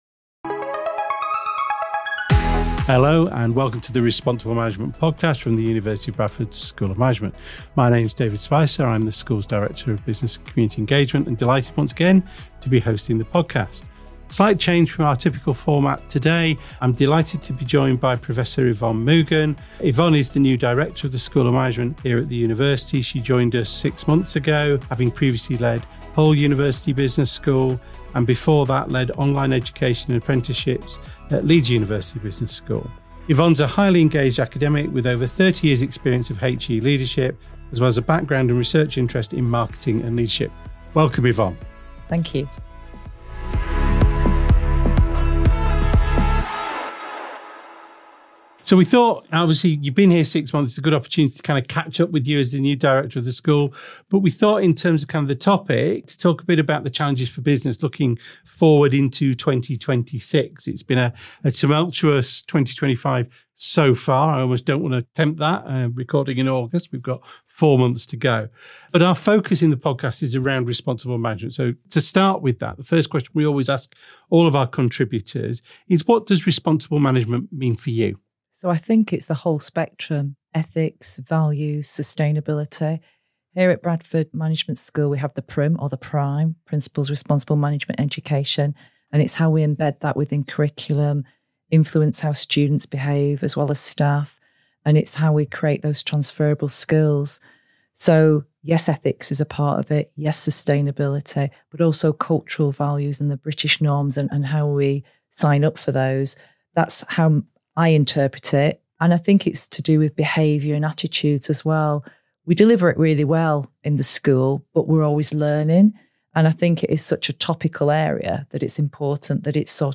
The conversation introduces our new Director as she has the opportunity to explore what responsible management means to her and the challenges and opportunities for modern business. Focus explores key themes of modern business such as AI and Sustainability whilst also bringing a wider view onto staying ahead of the curve.